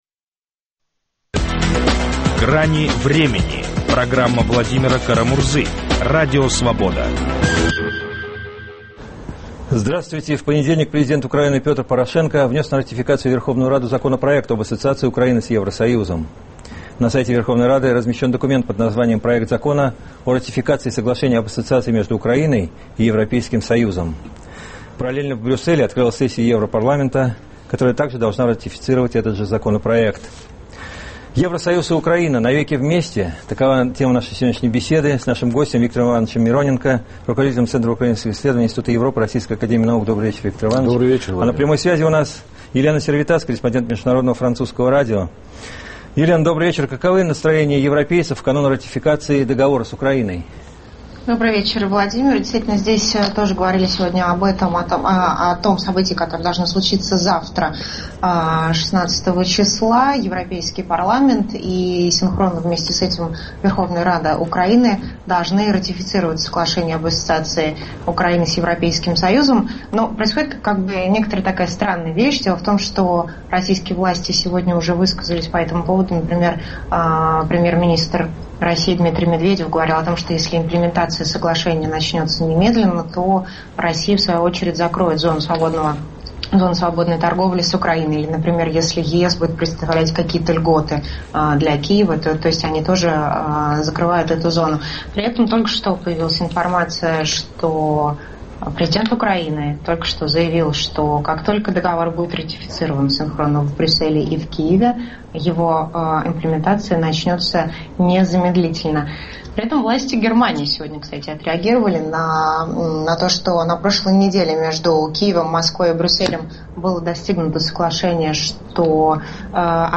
В канун ратификации Европарламентом договора об ассоциации Украины и ЕС обсуждаем его плюсы и минусы. Беседуем с политологами